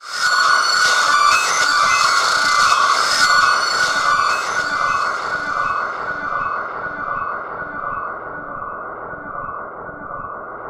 Index of /90_sSampleCDs/Spectrasonics - Bizarre Guitar/Partition F/10 FEEDBACK